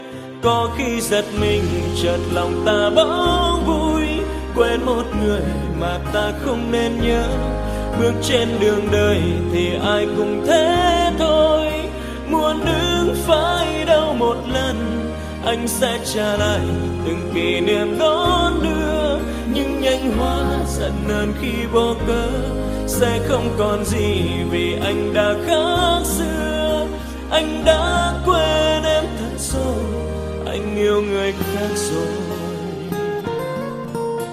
Nhạc Trẻ.